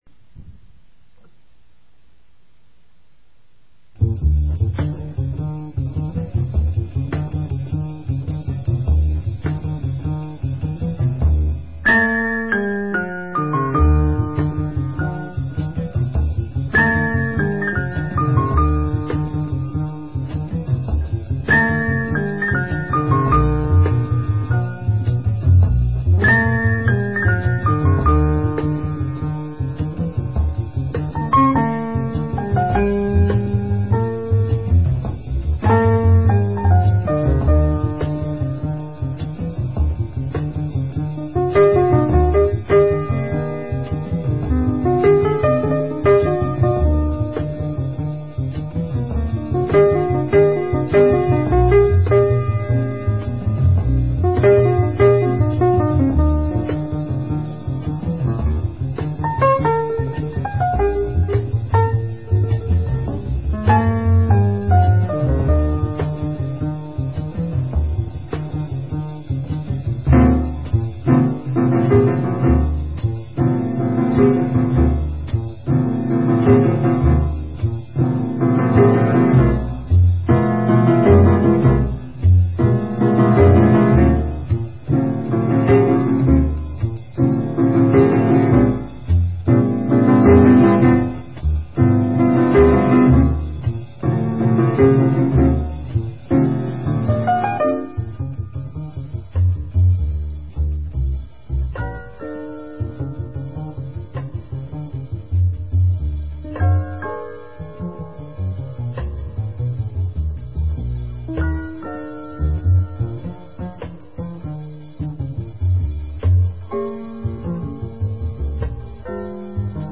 piano
bass
drums
These selections are taken from a pre-production tape.